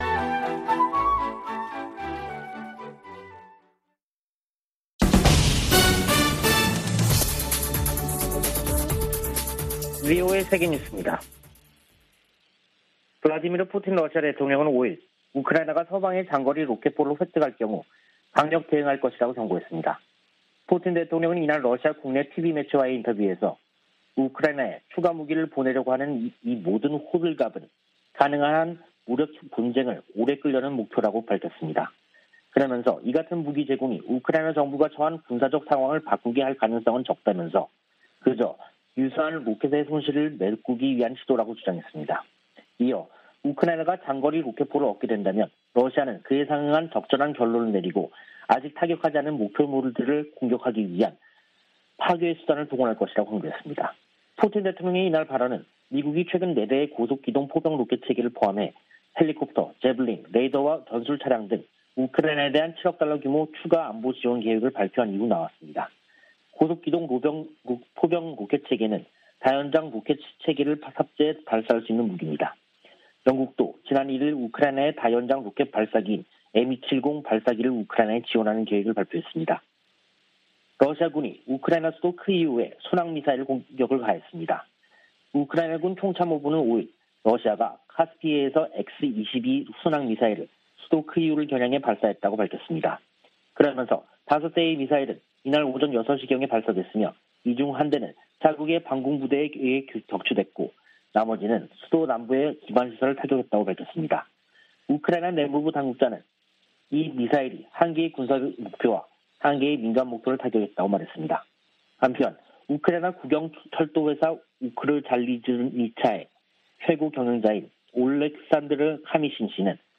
VOA 한국어 간판 뉴스 프로그램 '뉴스 투데이', 2022년 6월 6일 3부 방송입니다. 미국과 한국은 북한의 5일 단거리 탄도 미사일 발사에 대응해 6일 지대지 미사일 8발을 사격했습니다. 북한이 7차 핵실험을 준비하고 있다고 미한 당국이 평가하고 있는 가운데 미국의 최첨단 정찰기가 출격했습니다. 필립 골드버그 주한 미국대사가 취임선서를 하고 임기를 시작했습니다.